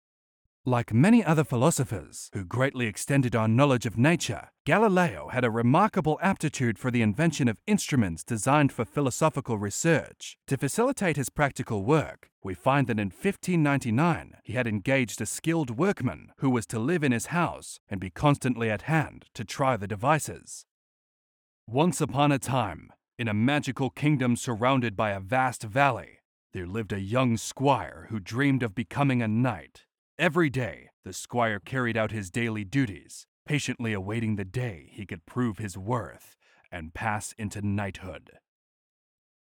解说旁白